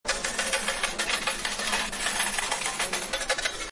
spin.mp3